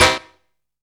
MO HRNS STAB.wav